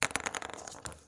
乒乓球 " 乒乓球3
描述：14号塑料乒乓球从6英寸下降。到一张木桌上。记录到逻辑 16/441
Tag: 离奇 古怪 乒乓球 桌上 节奏 中国平安 声音 紧凑